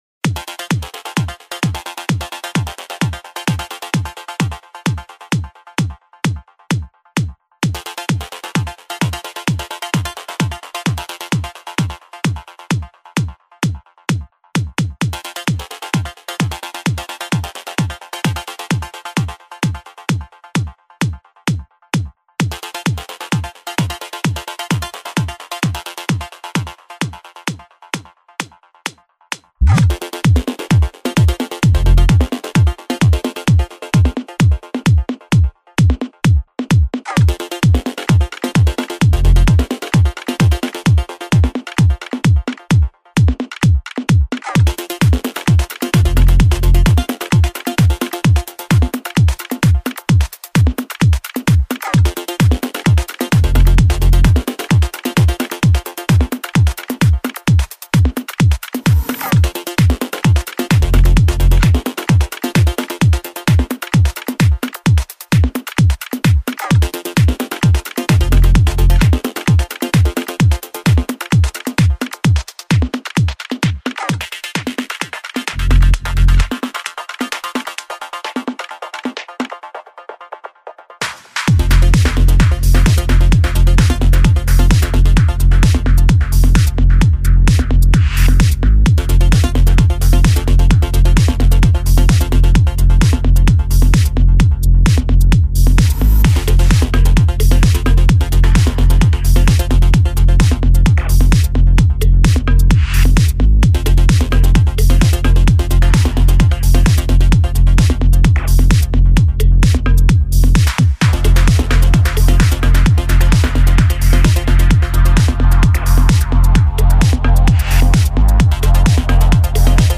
música electrónica israelí